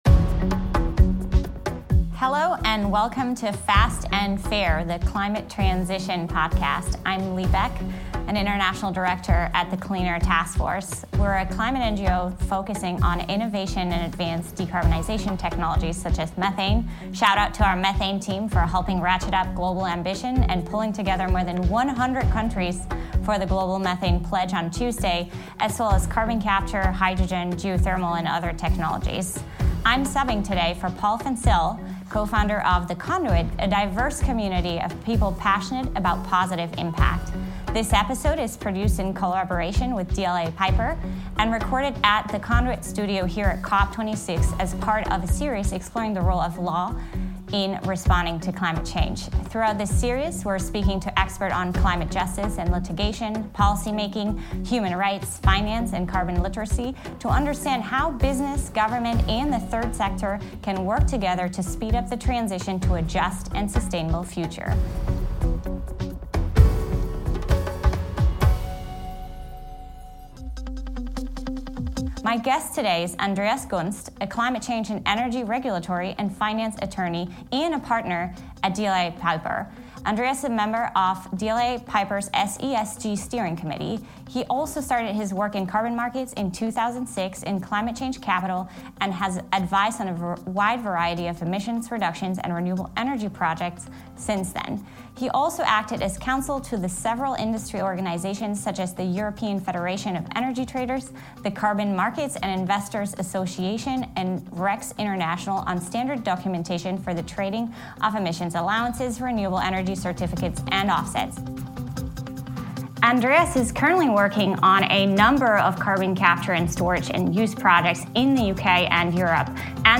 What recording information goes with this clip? This episode is produced in collaboration with DLA Piper, and recorded at The Conduit Studio at COP26, as part of a series exploring the role of the law in responding to climate change.